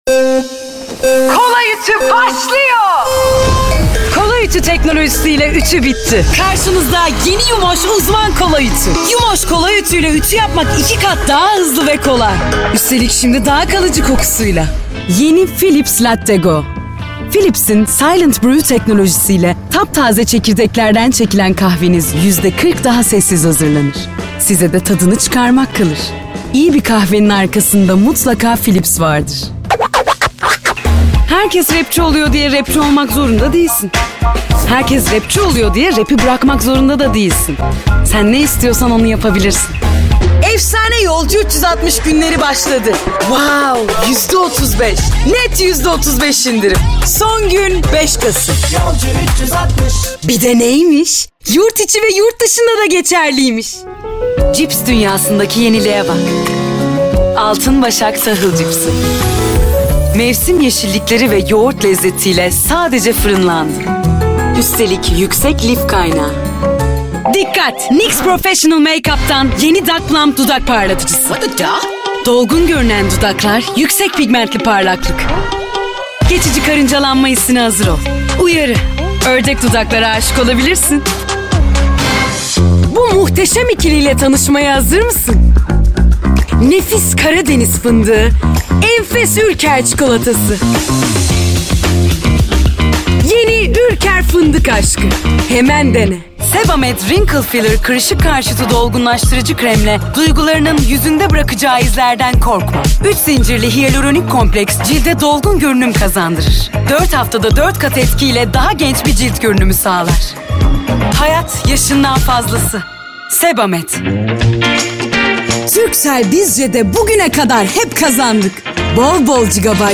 Kadın Genç Kadın
DEMO SESLERİ
Canlı, Eğlenceli, Fragman, Karakter, Seksi, Animasyon, Vokal, Promosyon, Sıcakkanlı, Tok / Kalın, Genç, Dış Ses,